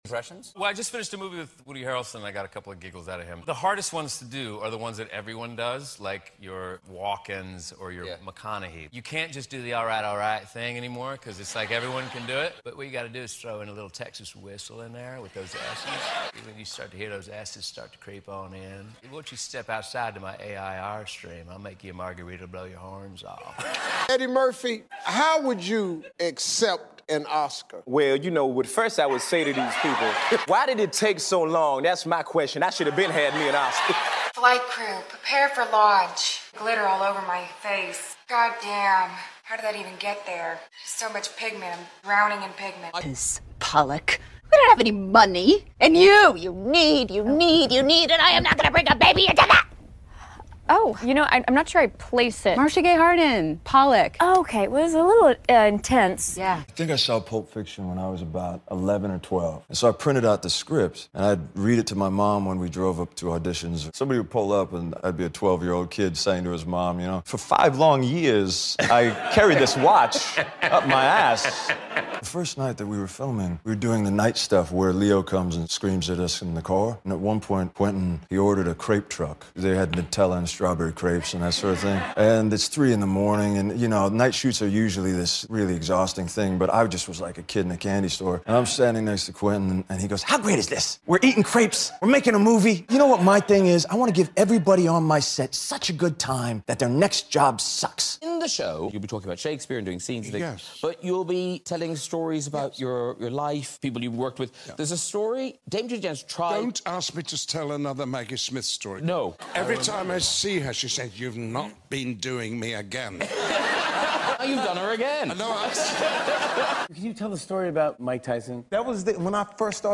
Celebrities Impersonating Each Other